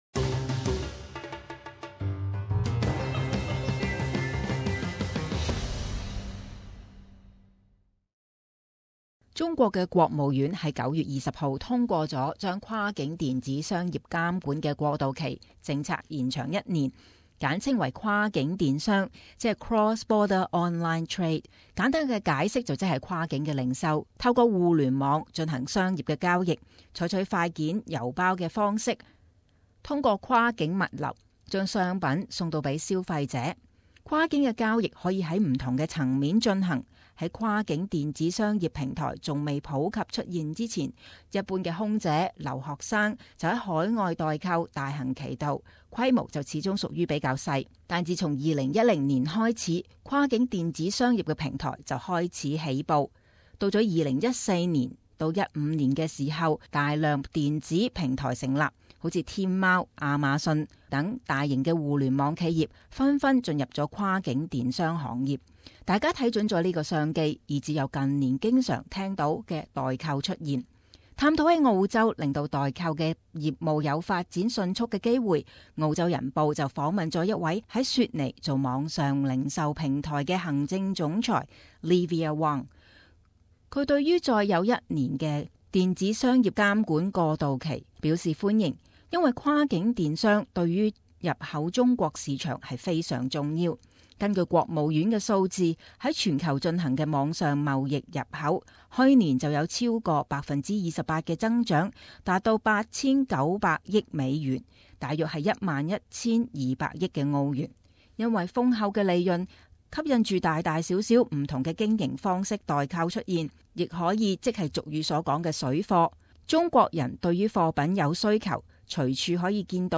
【時事報導】代購服務